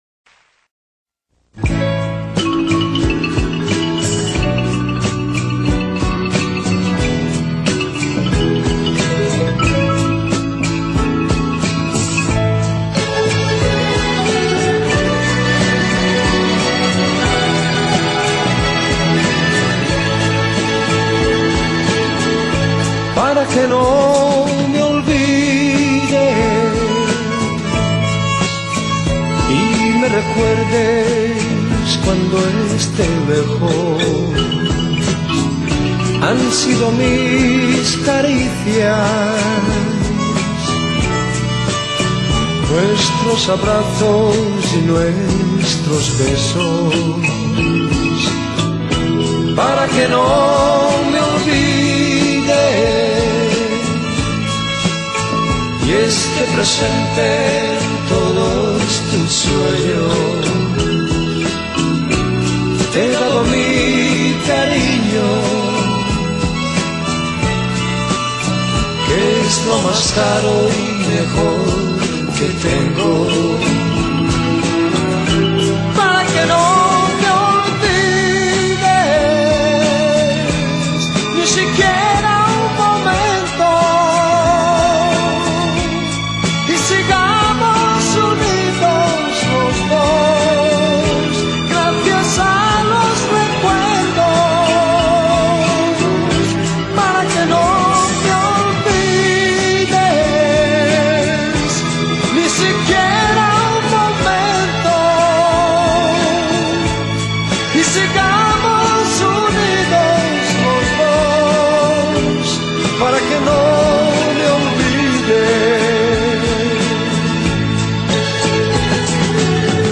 Balada romantica en español